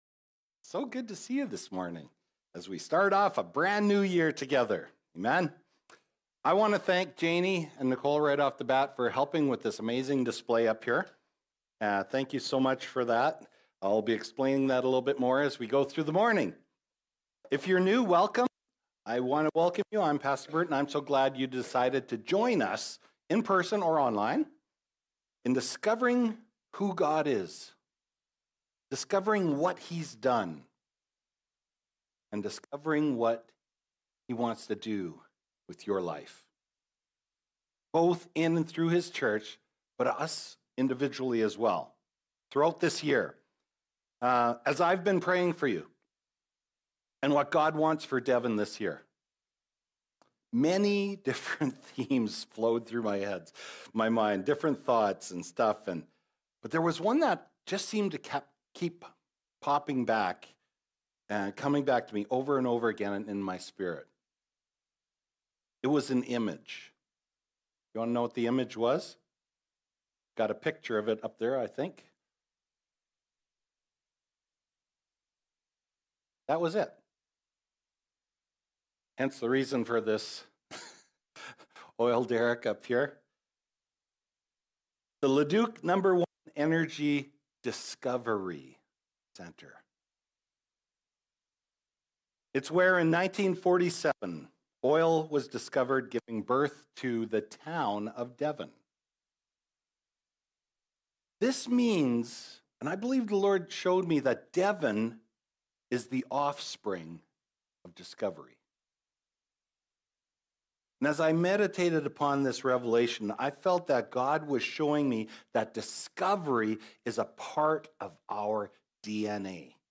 Sermons | Devon Community Church